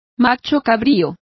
Complete with pronunciation of the translation of goats.